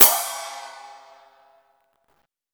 Crashes & Cymbals
Dks_Cym.wav